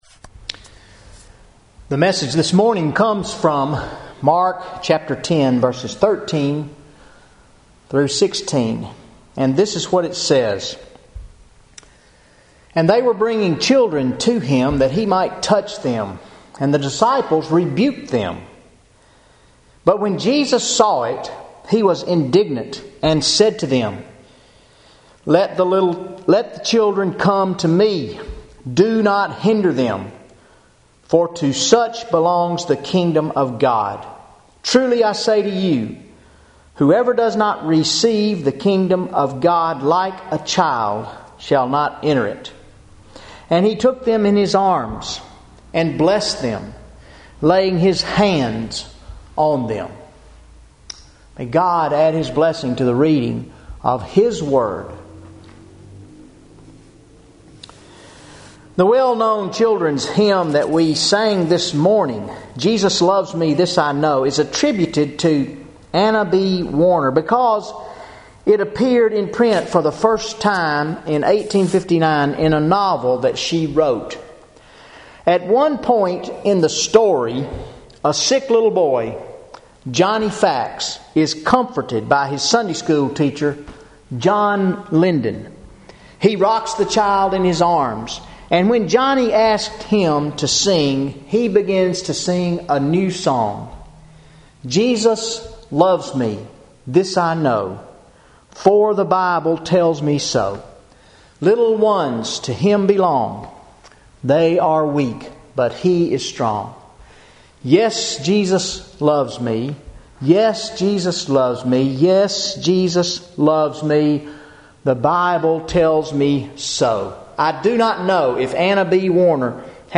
0 Comments VN810133 Sermon Audio Previous post Sermon March 3